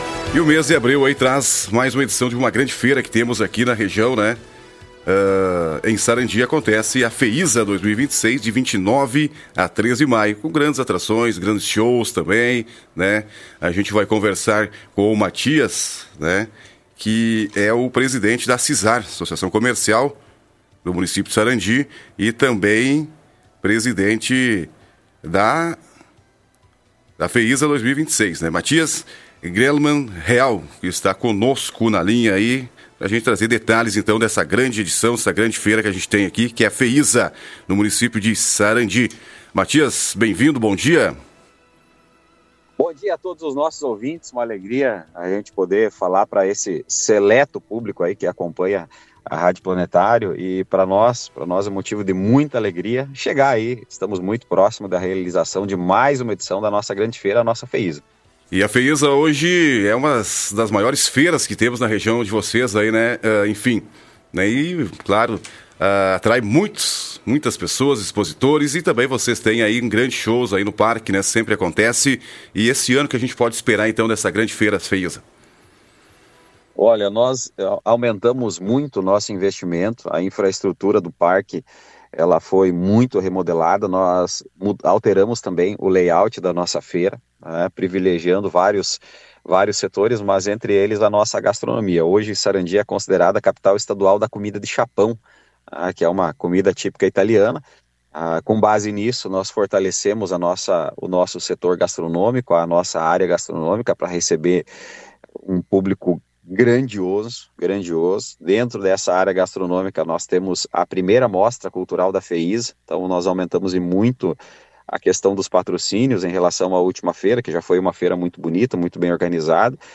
Em entrevista à Rádio Planetário